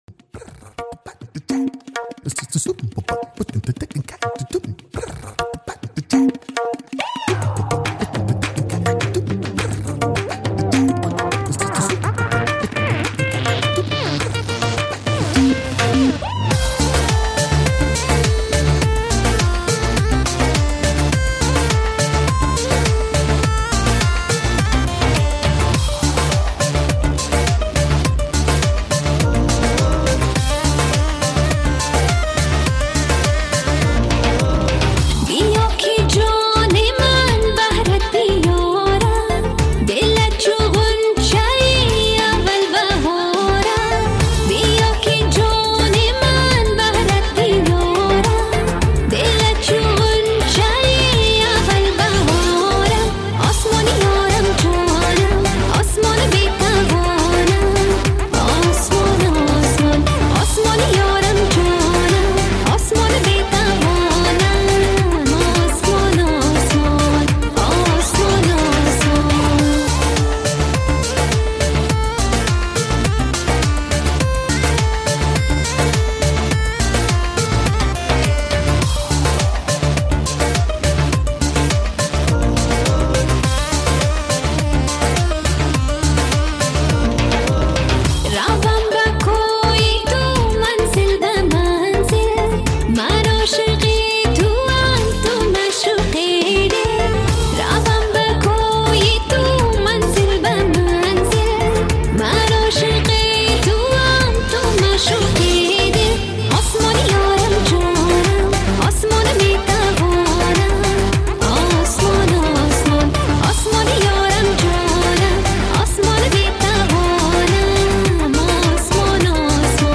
Afghan Music